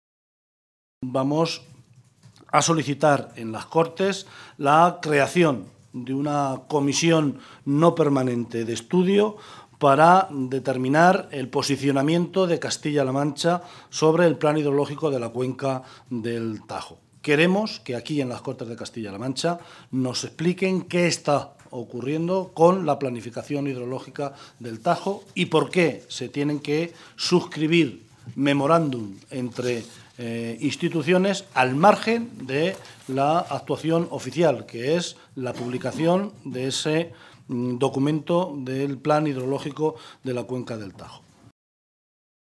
José Luís Martínez Guijarro, portavoz del Grupo Parlamentario Socialista
Guijarro ha hecho esta denuncia esta mañana, en una comparecencia ante los medios de comunicación, en Toledo, en la que ha acusado a Cospedal “de intentar engañarnos otra vez a todos los castellano-manchegos, vendiendo las supuestas bondades del Plan de cuenca oficial del río Tajo, mientras, con opacidad y oscurantismo, está conociendo y consintiendo que el Ministerio y las comunidades autónomas de Murcia y Valencia negocien un documento que perpetúa el trasvase y, a la postre, es más importante que el propio documento sobre el Plan de cuenca que se conoció la semana pasada”.